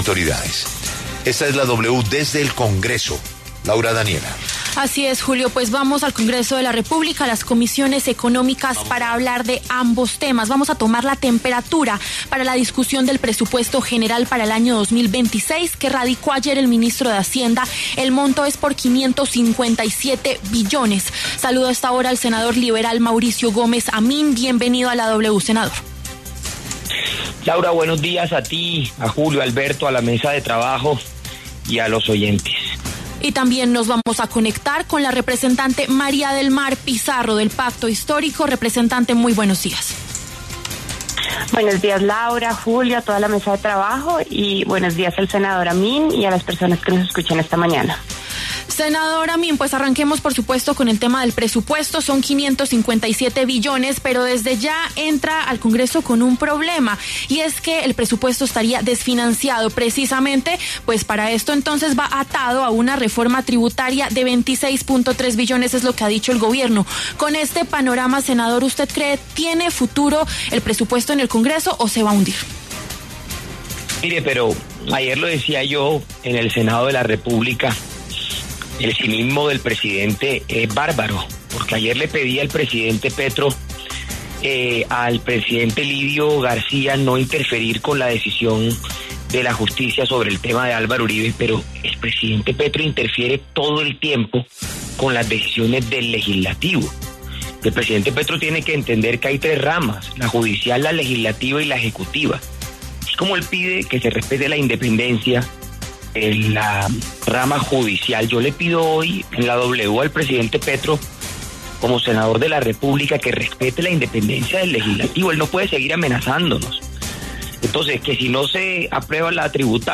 Los congresistas María del Mar Pizarro y Mauricio Gómez Amín debatieron en La W sobre el recientemente radicado Presupuesto General de la Nación.